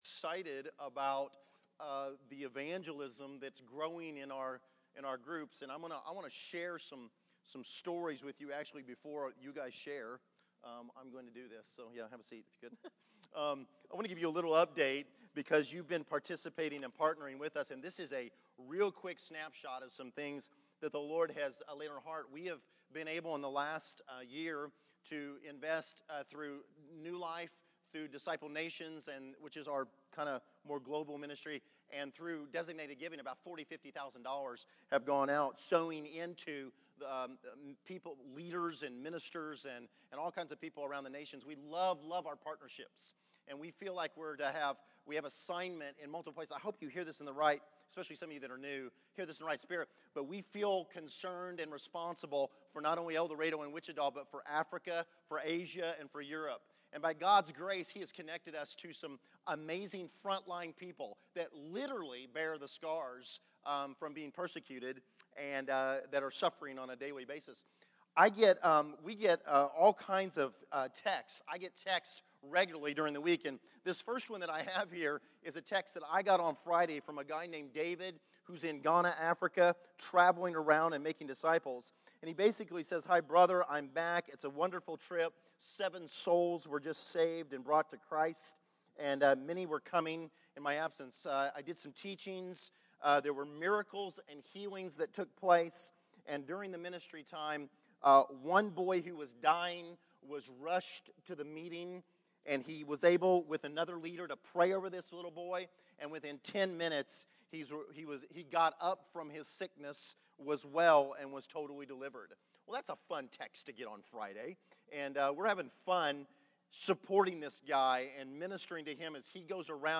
Testimonies